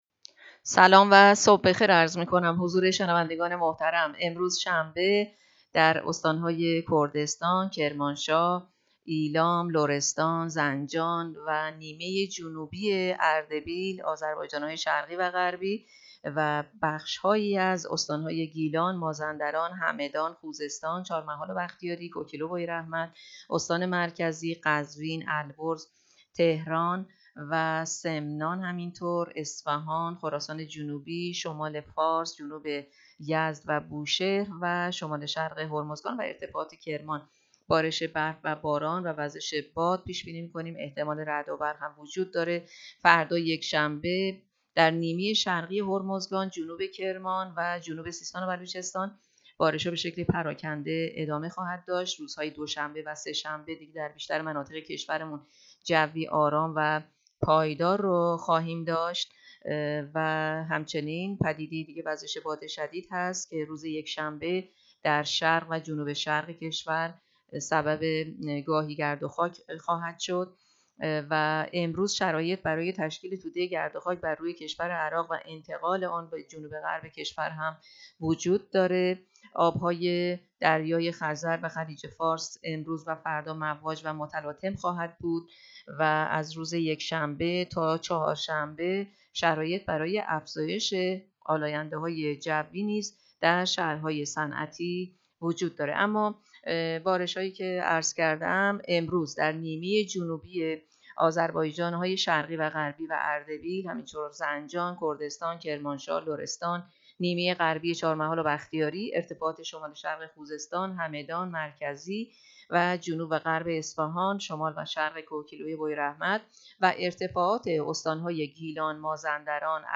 گزارش رادیو اینترنتی از آخرین وضعیت ترافیکی جاده‌ها تا ساعت ۹ اول دی؛